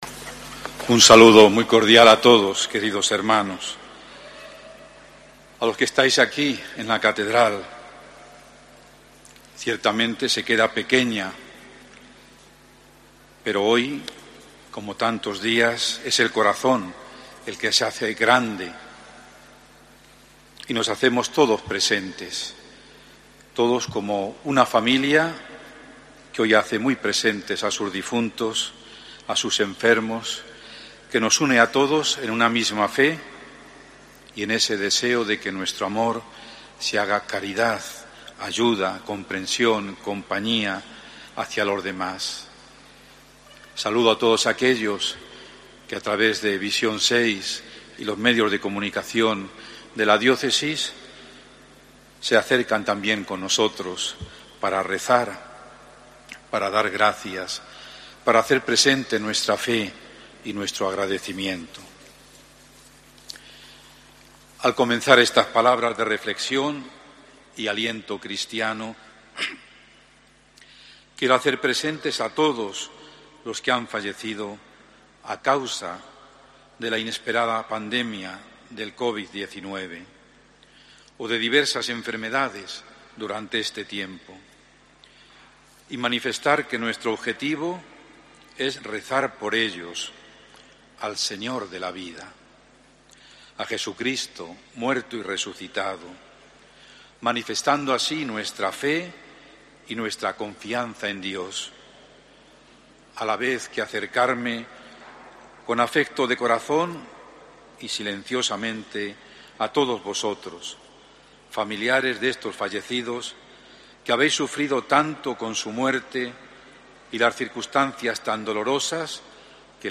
HOMILIA
Albacete - Chinchilla - San Pedro